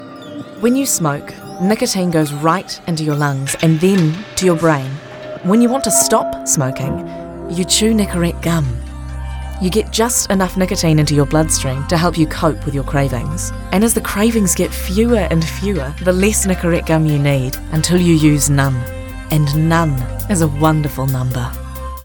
Her sound is very easy on the Ear.